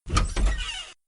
Added AIM sfx